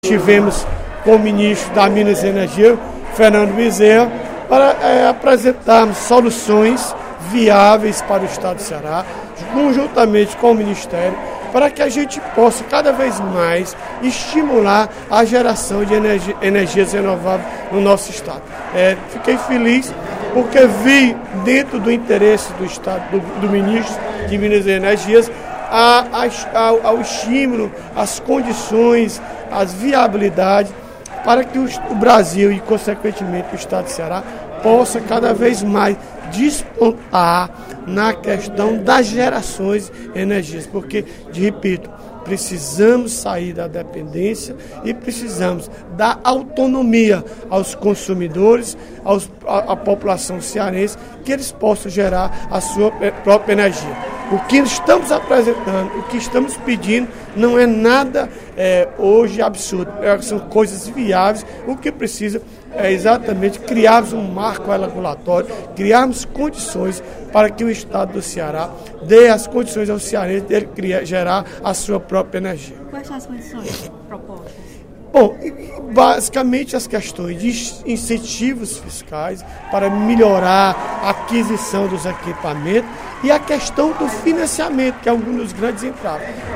O deputado Odilon Aguiar (PMB) propôs nesta terça-feira (12/07),  no primeiro expediente da sessão plenária, soluções viáveis para estimular a geração de energias renováveis no Estado do Ceará.